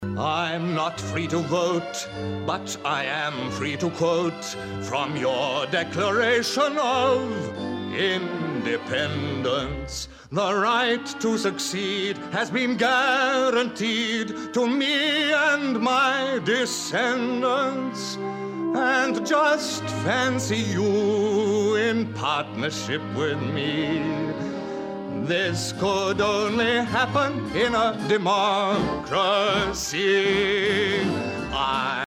Original Cast